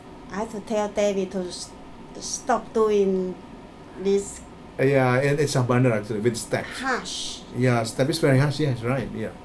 S1 = Taiwanese female S2 = Indonesian male Context: They are talking about different kinds of outdoors exercise.